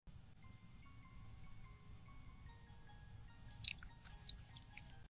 Maçã Do Amor 🍎 ❤comendo Sound Effects Free Download